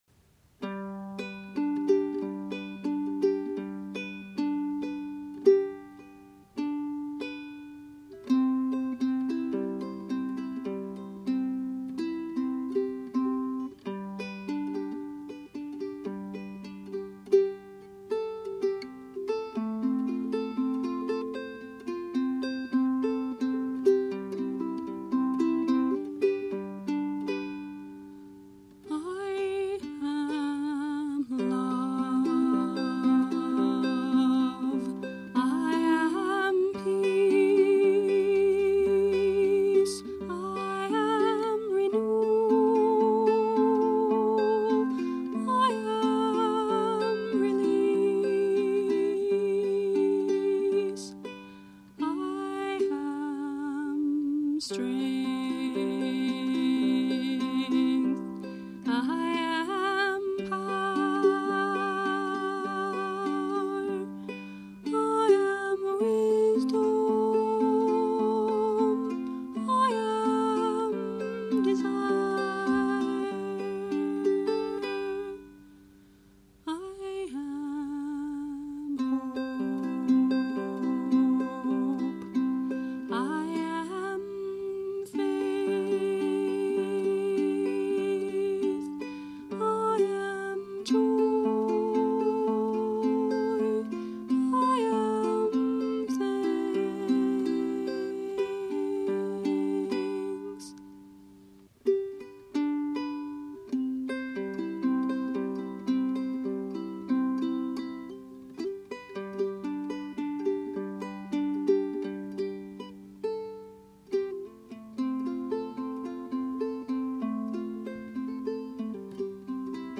Instrument: Lady – tenor Flea ukulele